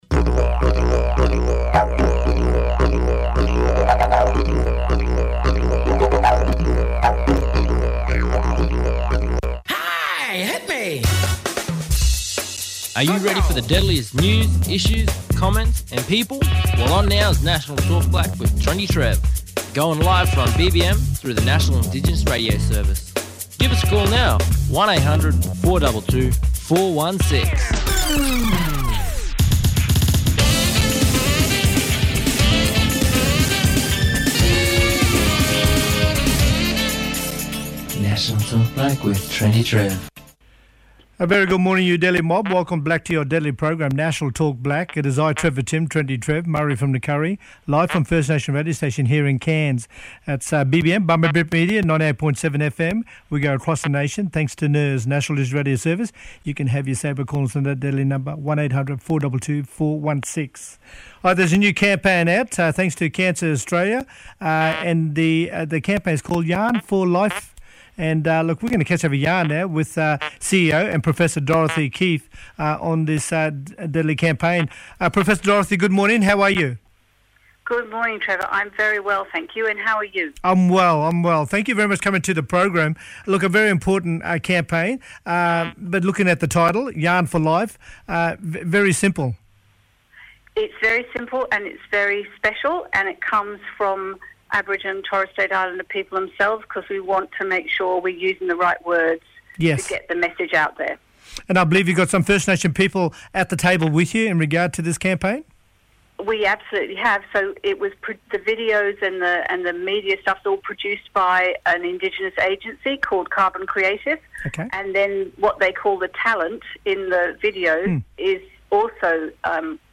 Professor Dorothy Keefe, Cancer Australia CEO talking about the Yarn for Life Cancer Campaign. Yarn for Life is a campaign to reduce the impact of cancer within Aboriginal and Torres Strait Islander communities by encouraging and normalising conversations about the disease.
Phil Green, Queensland Privacy Commissioner talking about Privacy Awareness week which runs from the 3rd – 9th May 2021, this years theme is: Make privacy a priority.